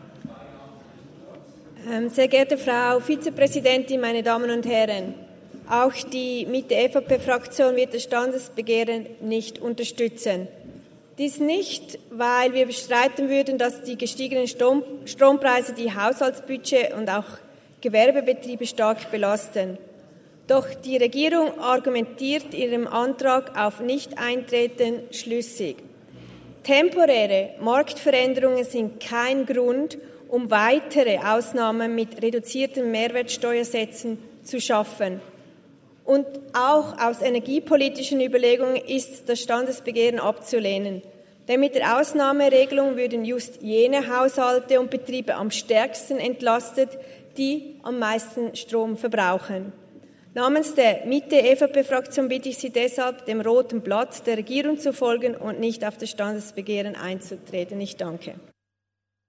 Session des Kantonsrates vom 27. bis 29. November 2023, Wintersession
28.11.2023Wortmeldung